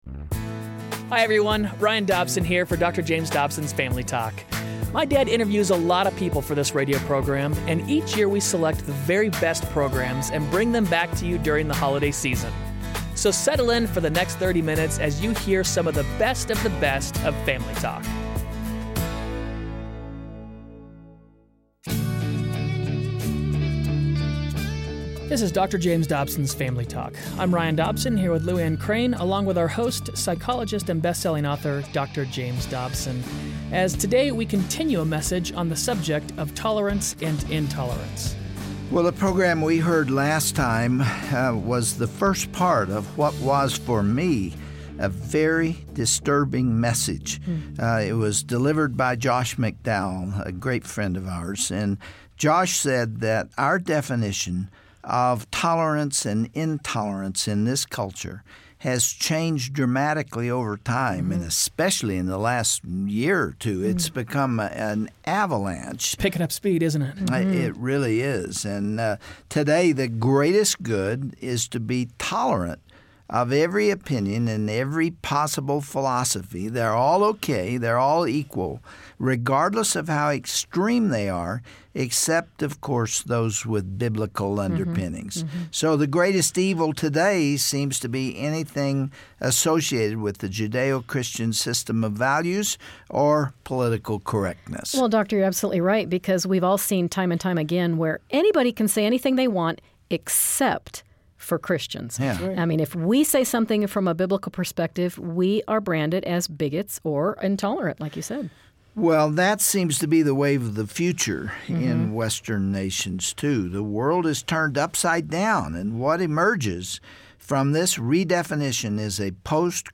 Host Dr. James Dobson
Guest(s): Josh McDowell